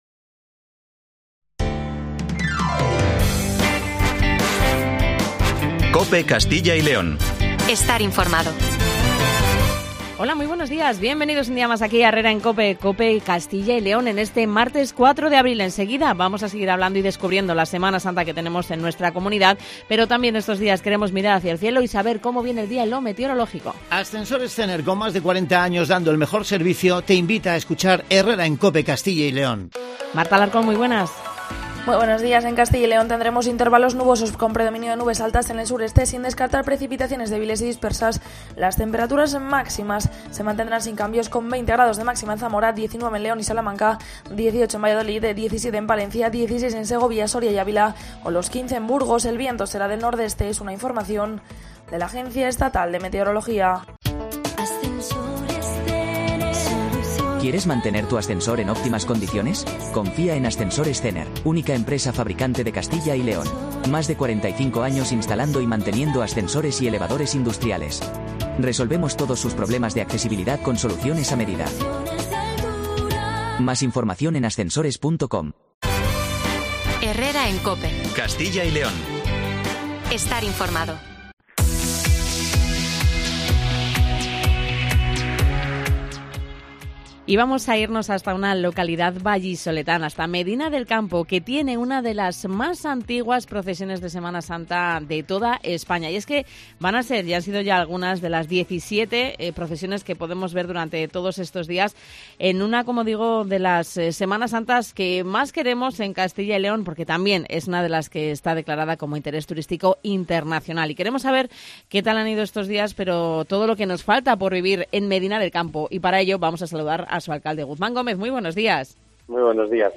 AUDIO: Conocemos más sobre la Semana Santa de Medina del Campo de la mano de su Alcalde, Guzmán Gómez.